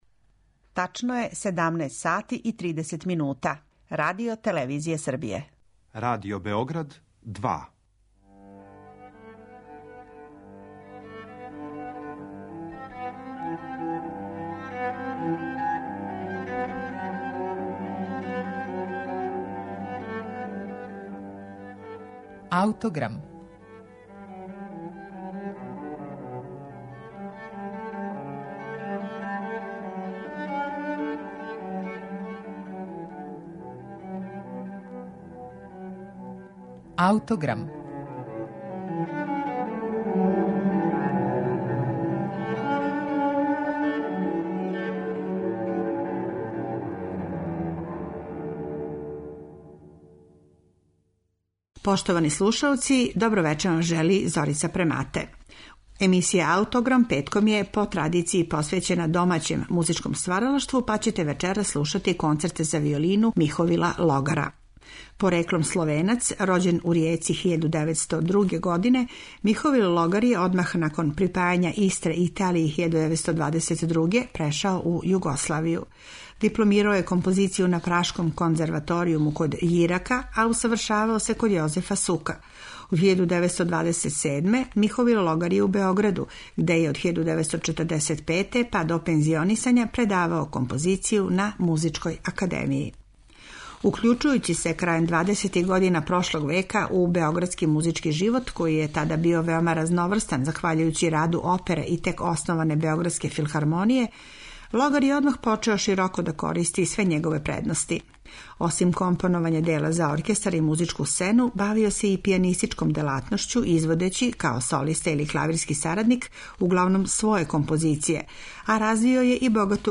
У данашњој емисији слушаћете виолинске концерте нашег неокласичара МИХОВИЛА ЛОГАРА.
Емисију ће започети његов Концерт за виолину и оркестар у ха-молу из 1954. године, који ћете слушати са нашег архивског снимка.